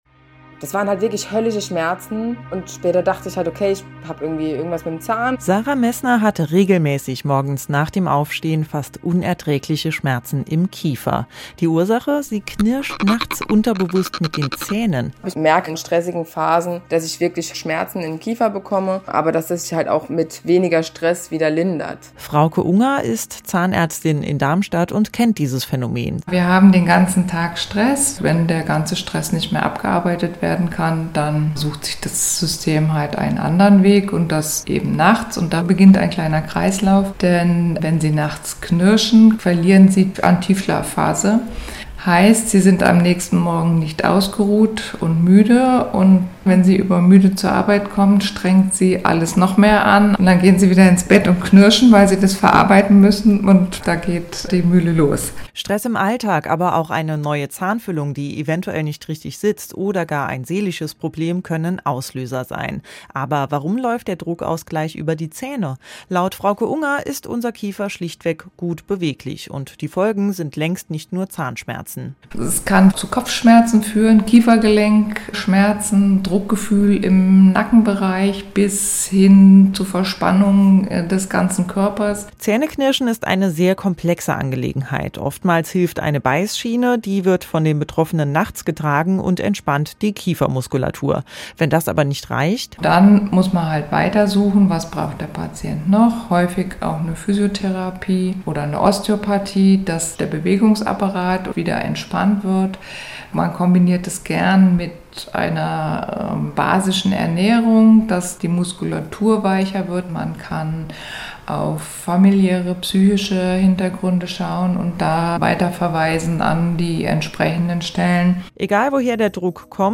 Interview zum Thema Zähneknirschen
Interview des Hessischen Rundfunks